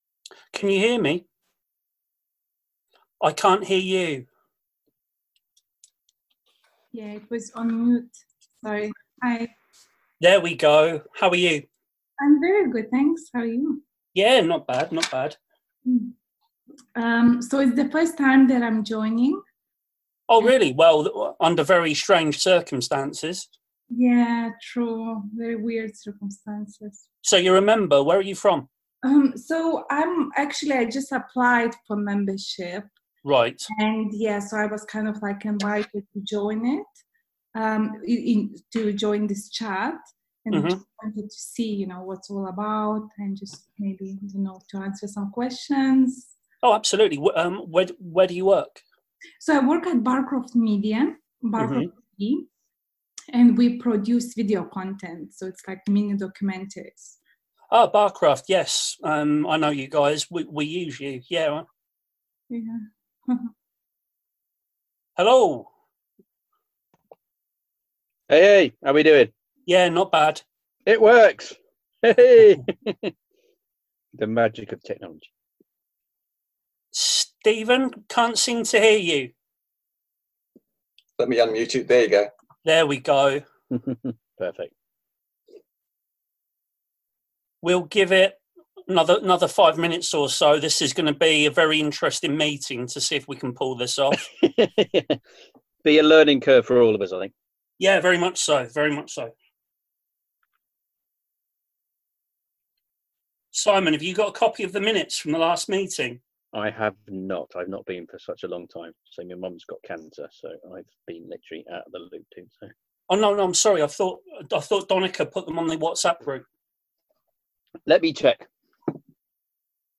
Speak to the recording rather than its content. The first online branch meeting took place on Tuesday, 31 March 2020 with guests from PressPad talking about their project. The other advantage of online meetings is that they’re easy to record.